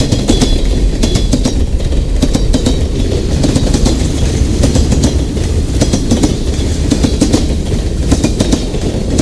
train_tunnel.wav